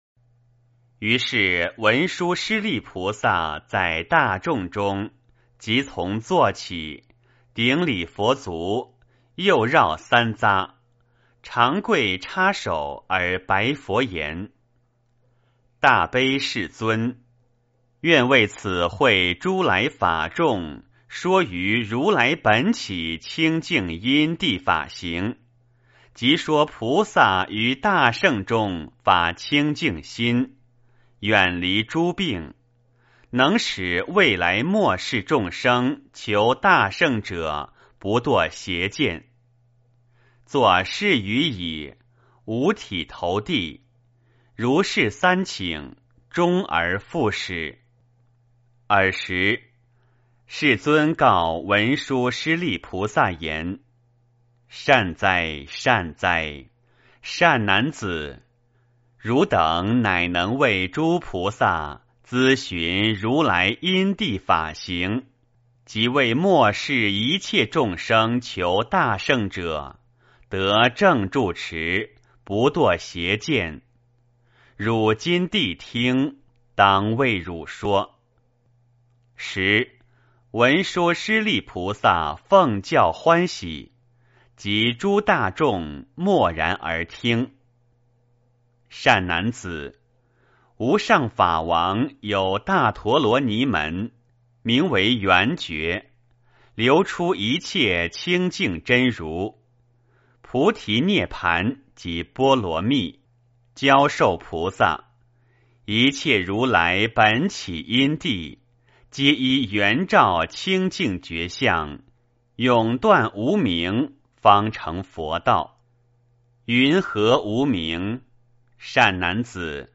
圆觉经-01文殊师利菩萨 - 诵经 - 云佛论坛